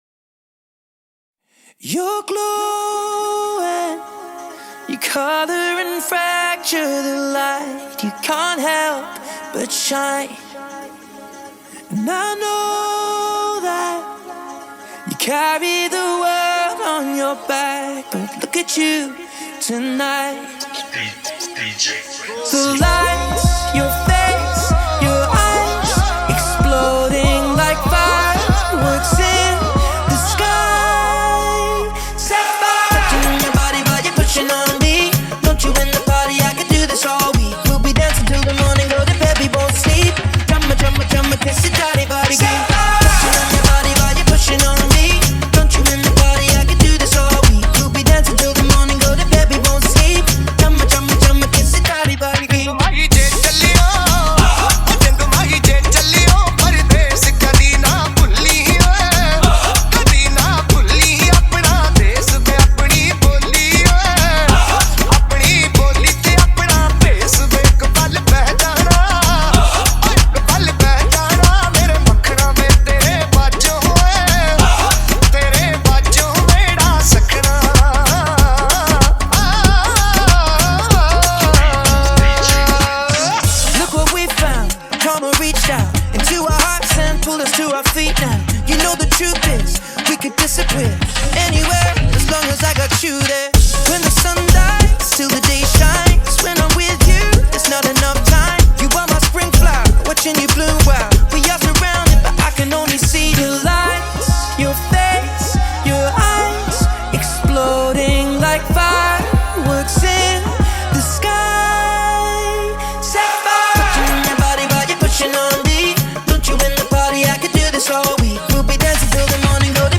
punjabi-diwali-music.mp3